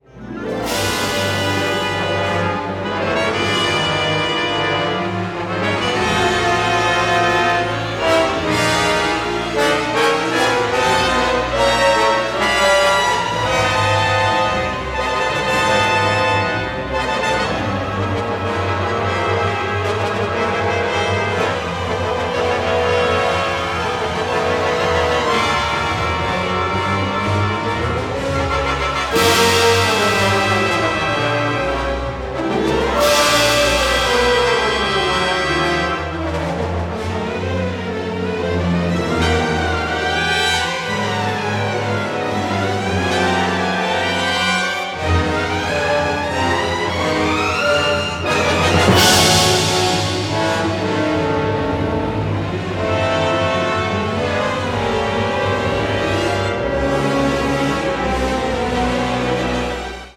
a rerecording of music